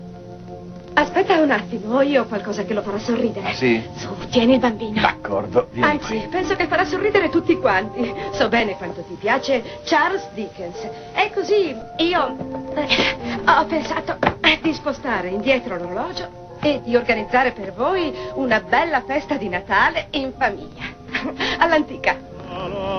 nella soap-opera "Sentieri", in cui doppia Lisa Brown.